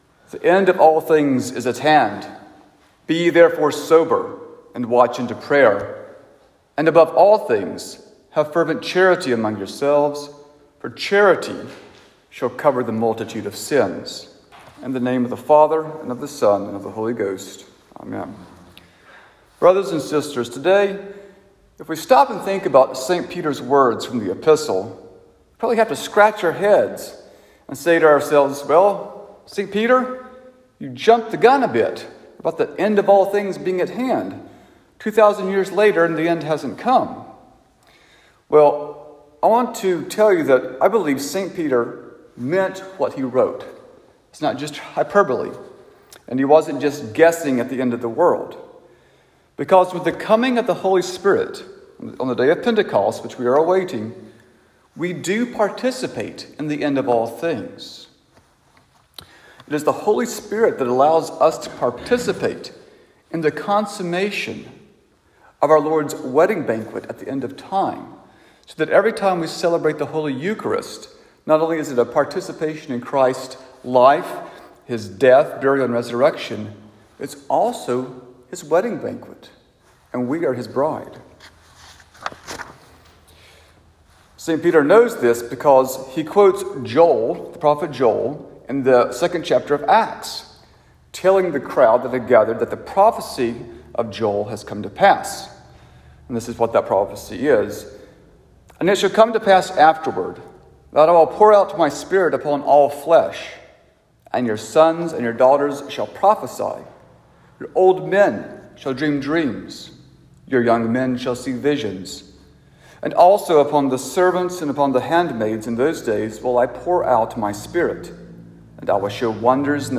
Saint George Sermons Sermon for the Sunday After Ascension